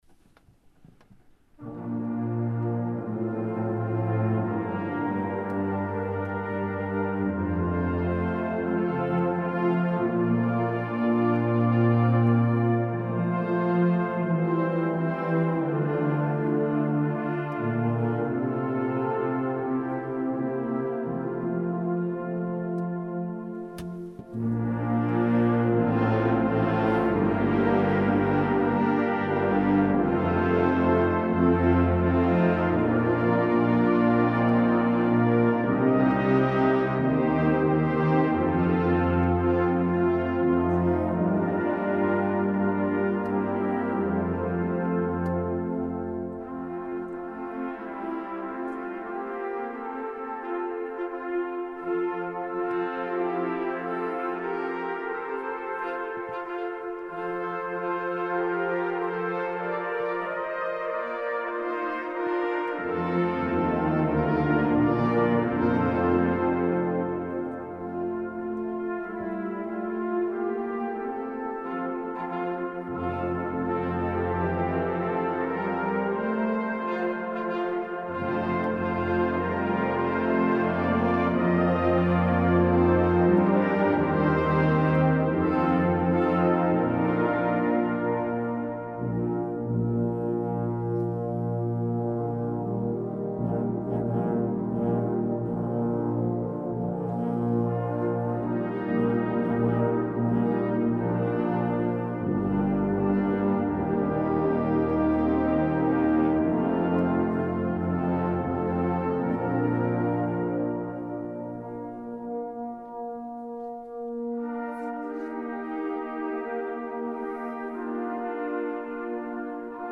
Bläsermusik proben
Die Aufnahmen wurden von verschiedenen Ensembles aufgenommen und zur Verfügung gestellt. Nicht mit dem Anspruch einer perfekten CD-Aufnahme, sondern als Hilfe für Chorleiterinnen und Chorleiter oder einzelne Mitspieler, um sich einen Klangeindruck der Stücke zu verschaffen.
Friedrich Silcher, Ensemble der Posaunenwarte, Gloria 2024 S. 20